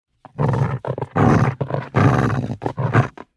دانلود صدای سگ 4 از ساعد نیوز با لینک مستقیم و کیفیت بالا
جلوه های صوتی